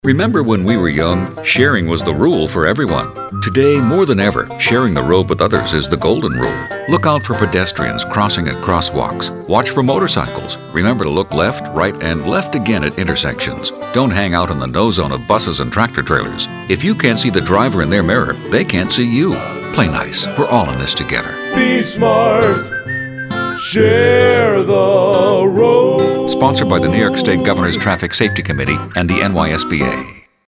Share the Road :30 Radio PSA.
ShareTheRoadEnglishRadio.mp3